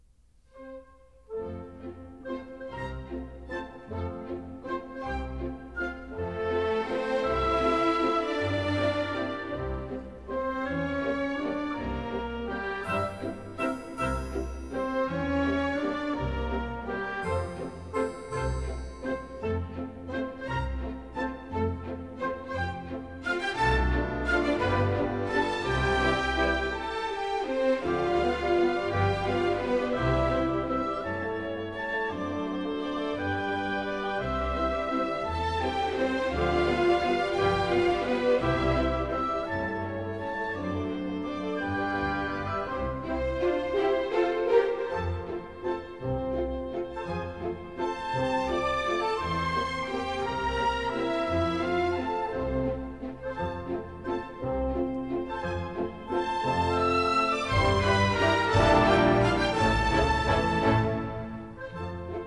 Stereo recording made in April 1960 in the
Orchestral Hall, Chicago